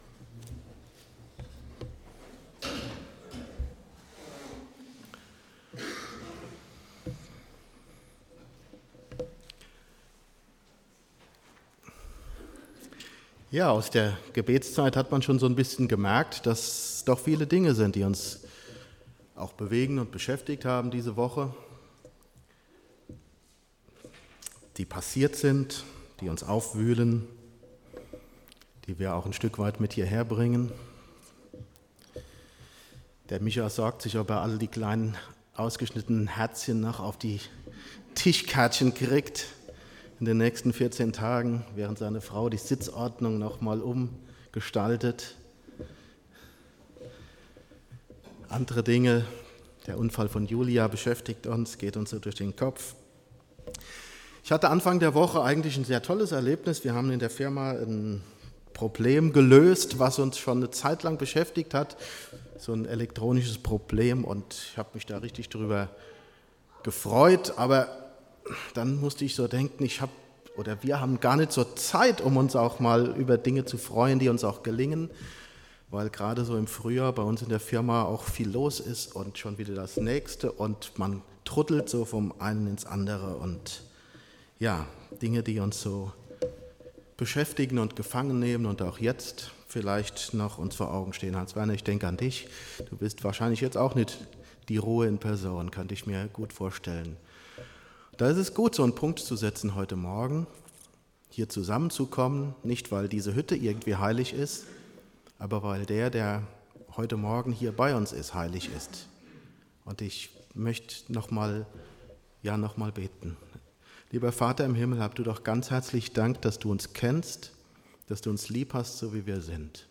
PREDIGTEN - Ev.